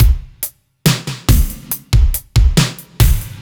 Index of /musicradar/french-house-chillout-samples/140bpm/Beats
FHC_BeatC_140-02.wav